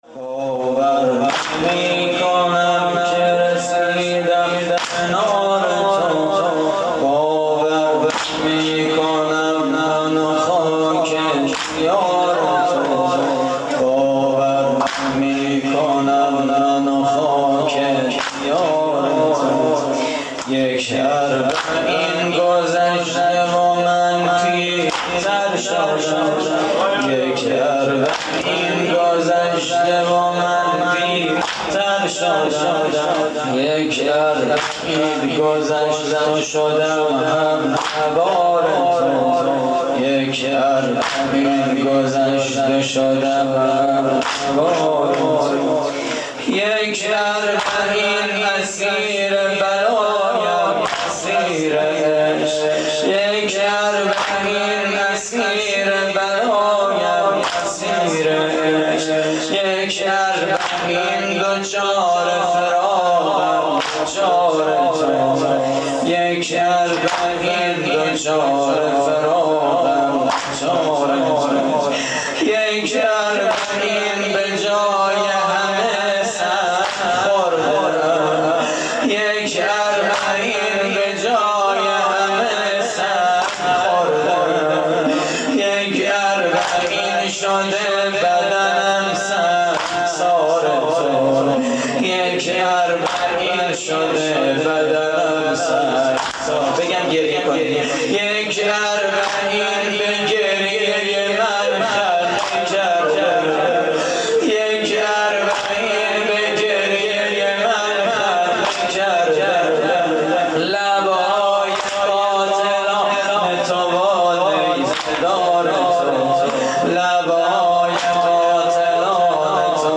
واحد: باور نمی‌کنم که رسیدم کنار تو
مراسم عزاداری اربعین حسینی